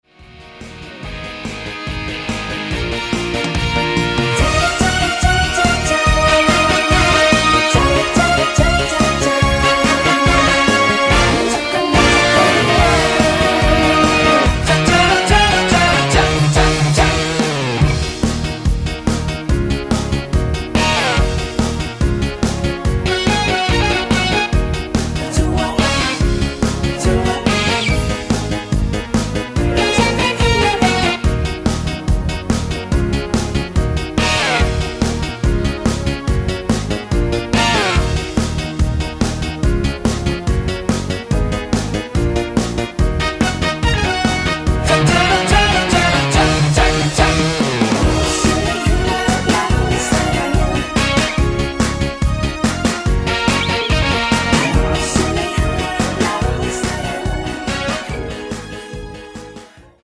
코러스 포함된 MR 입니다.(미리듣기 참조)
Ebm
앞부분30초, 뒷부분30초씩 편집해서 올려 드리고 있습니다.